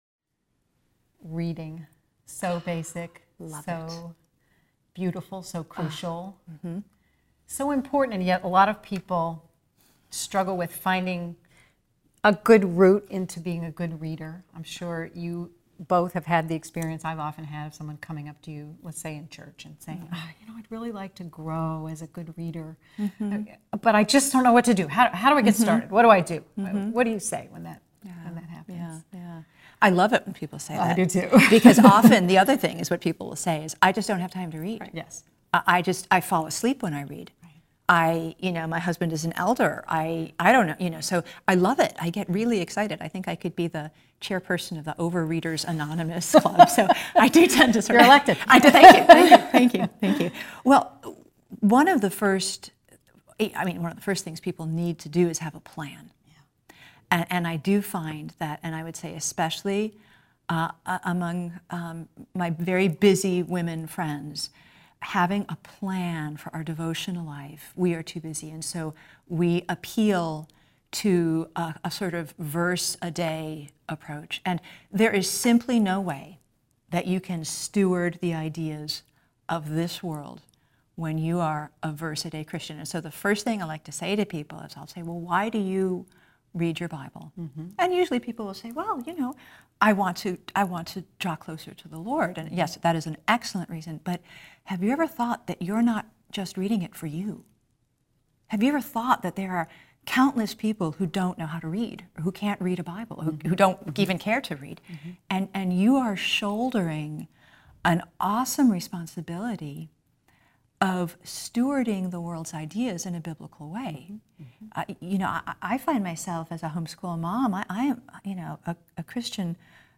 Watch the full 13-minute video or listen to these three women discuss busy life stages, Psalm singing, kids with disabilities, and more.